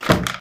STEPS Wood, Reverb, Run 04, Creak.wav